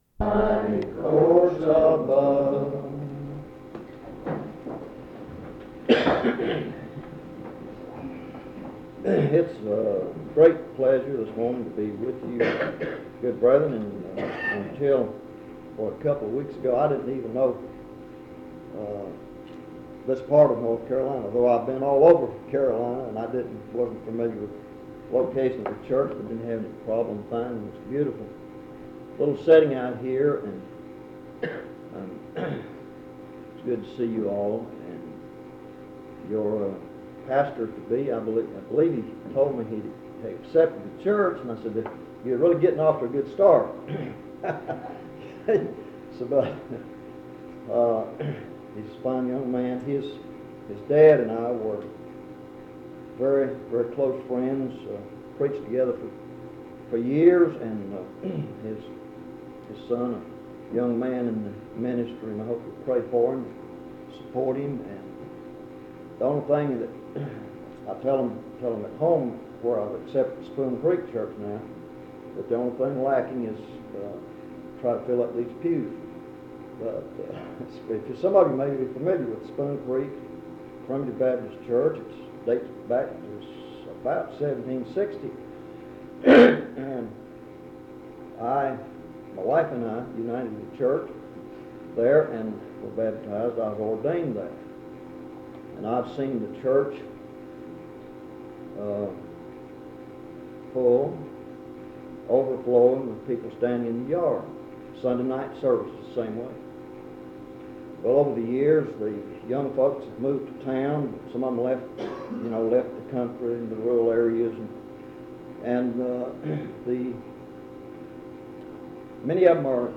In Collection: Monticello Primitive Baptist Church audio recordings Thumbnail Titolo Data caricata Visibilità Azioni PBHLA-ACC.002_004-B-01.wav 2026-02-12 Scaricare PBHLA-ACC.002_004-A-01.wav 2026-02-12 Scaricare